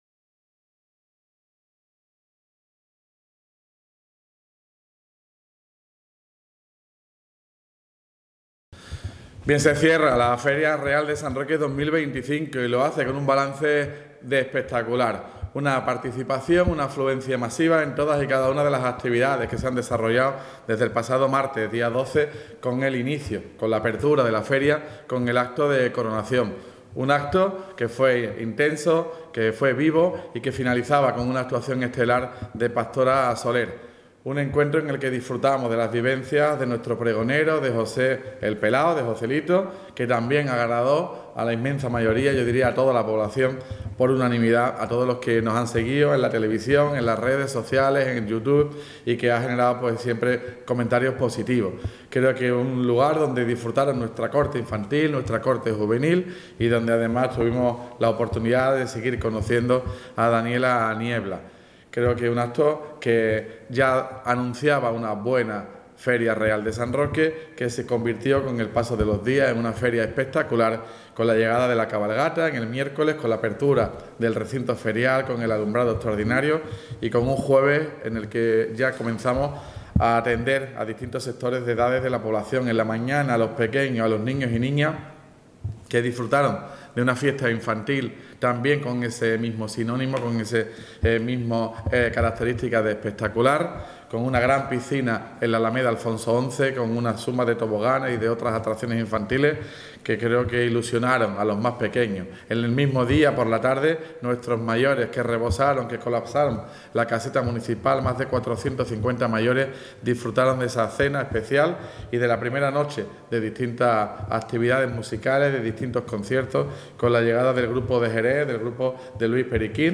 El primer edil, Juan Carlos Ruiz Boix, y la teniente de alcalde de Fiestas y Tradiciones, María del Mar Collado, han hecho este martes un “balance espectacular” de la Feria Real de San Roque 2025. Además, el alcalde ha felicitado a las fuerzas de seguridad y al personal municipal por la gran labor realizada estos días. Explicó Ruiz Boix que, desde la Coronación del martes pasado, la Feria Real ha tenido “una afluencia masiva” de personal a los distintos actos.